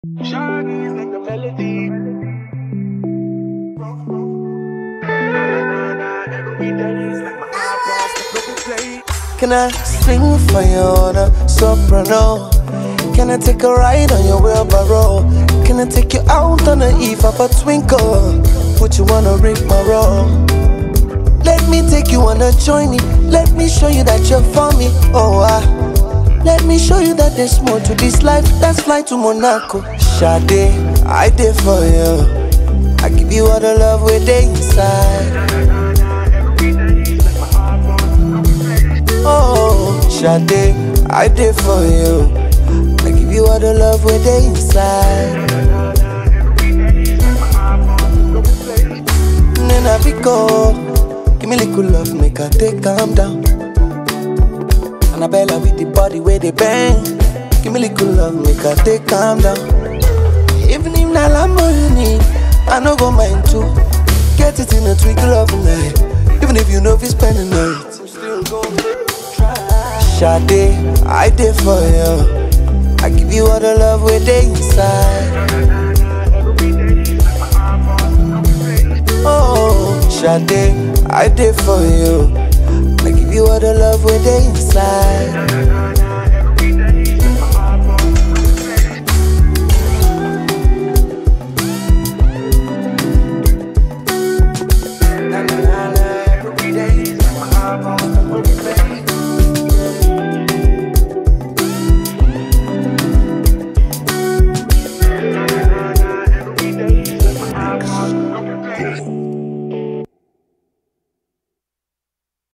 Afrobeats, R&B, and contemporary pop
Fans have praised the new single for its soothing vibe